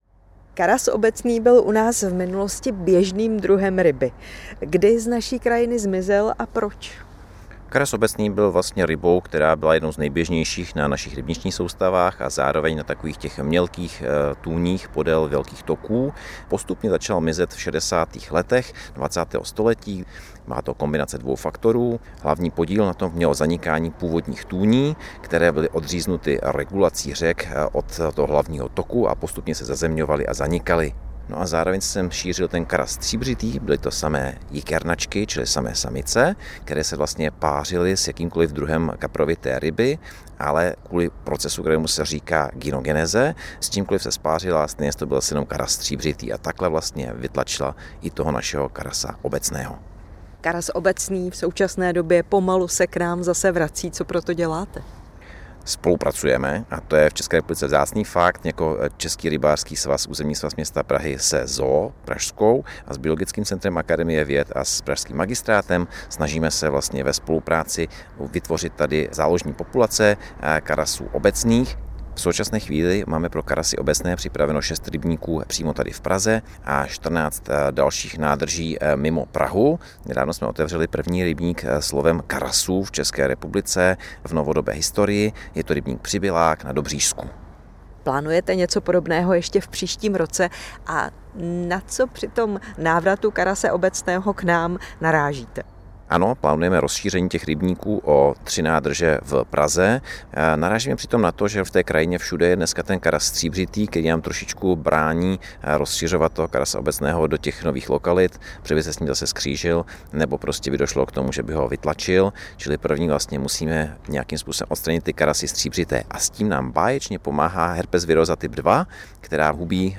Reportáže z jižních Čech, písničky na přání a dechovka.